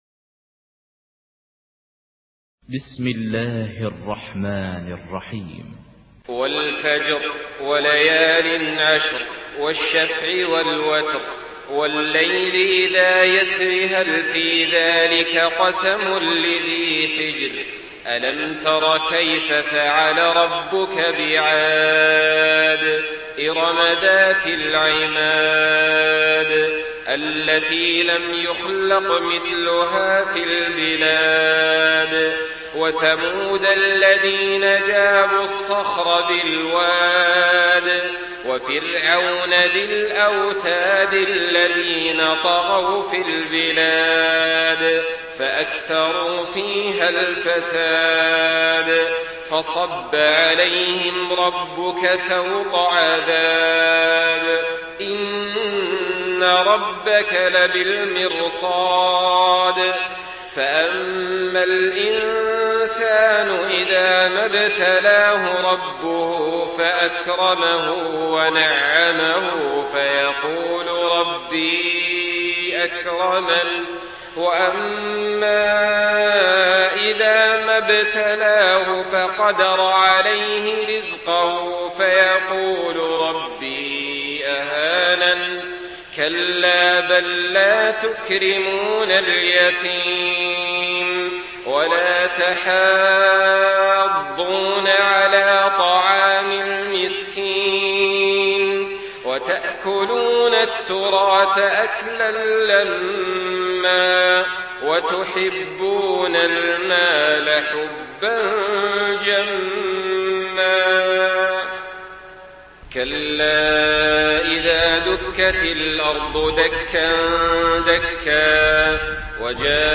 Surah Al-Fajr MP3 in the Voice of Saleh Al-Talib in Hafs Narration
Murattal